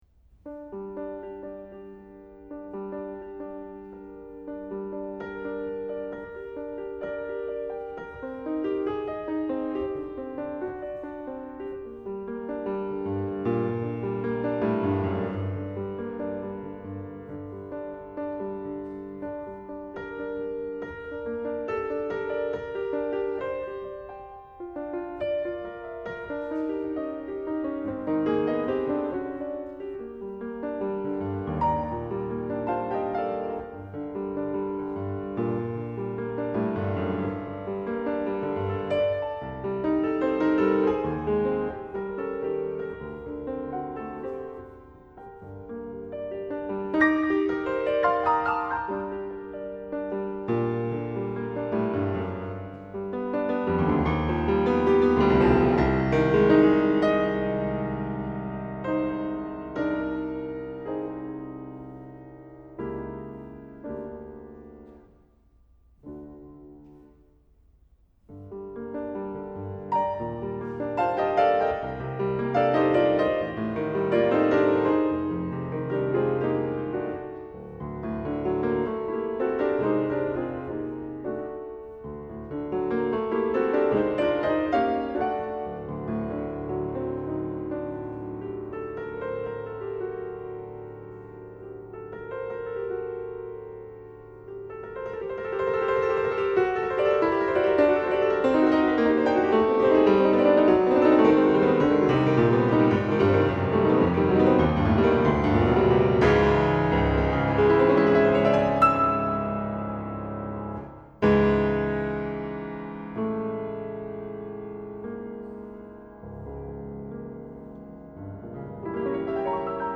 Recitals - July 24, 2008